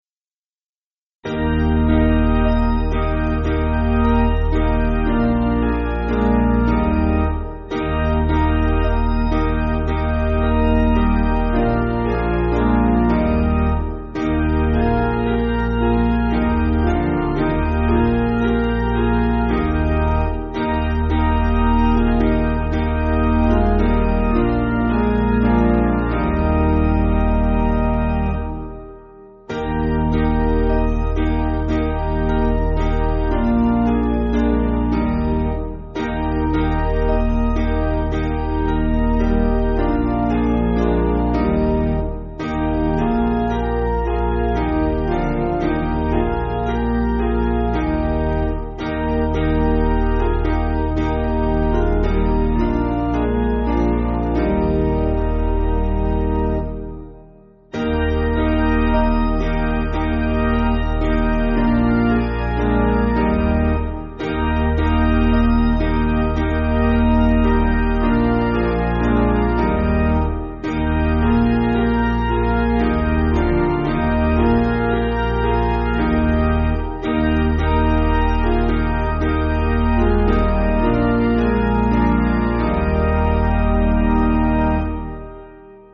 Basic Piano & Organ
(CM)   6/Eb